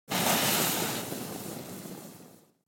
دانلود آهنگ آتشفشان 8 از افکت صوتی طبیعت و محیط
دانلود صدای آتشفشان 8 از ساعد نیوز با لینک مستقیم و کیفیت بالا
جلوه های صوتی